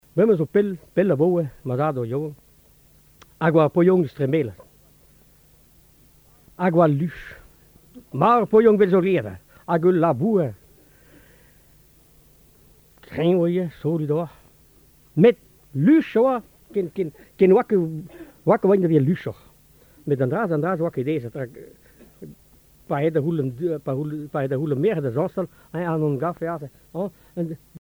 Genre conte
Catégorie Récit